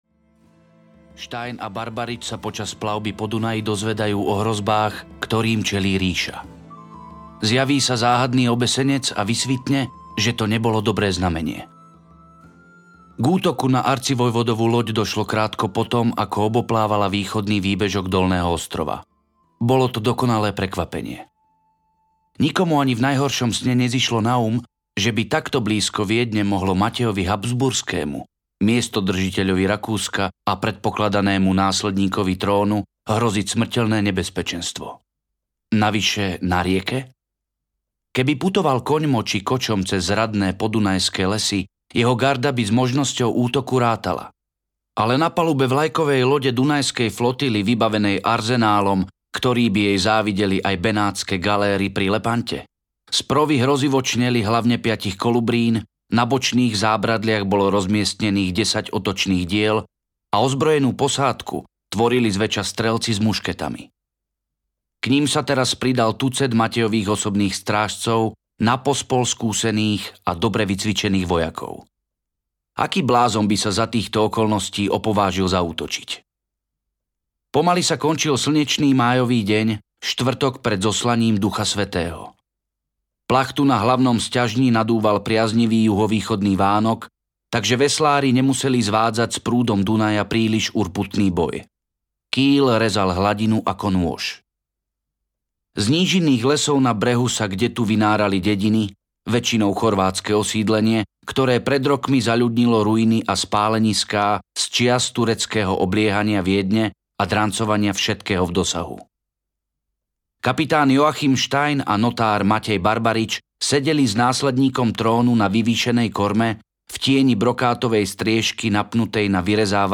Diabol v zrkadle audiokniha
Ukázka z knihy